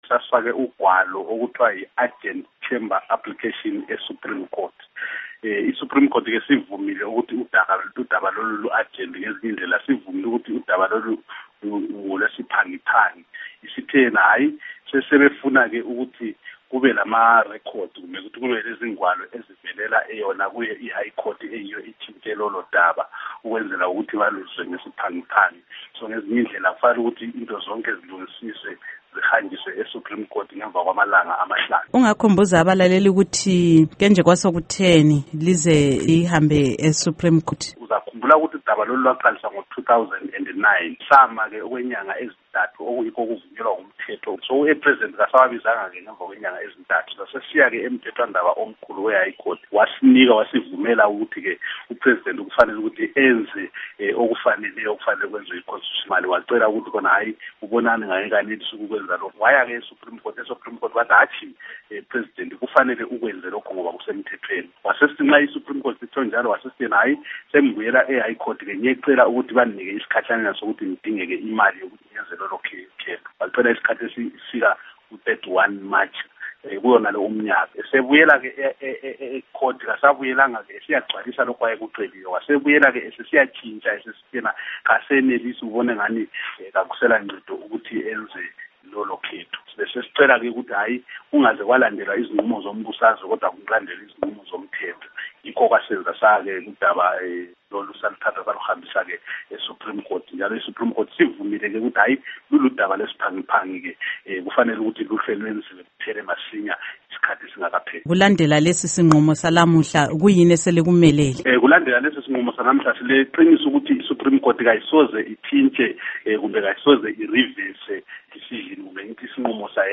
Ingxoxo LoMnu. Abednico Bhebhe